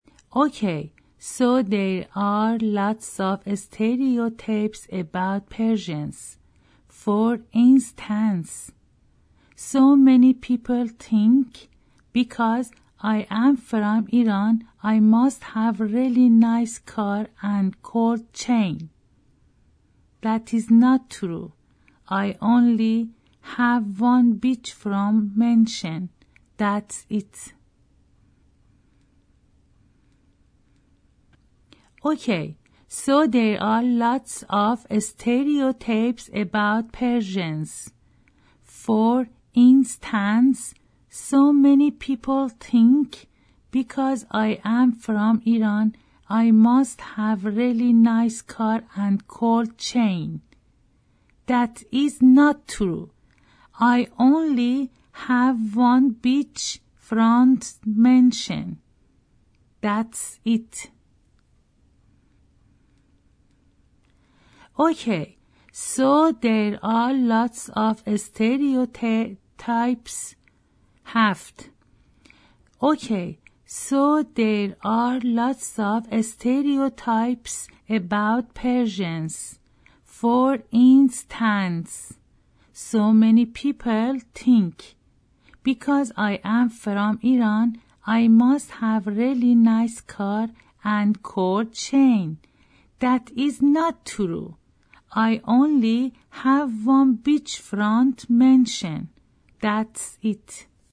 Female
English-Persian accent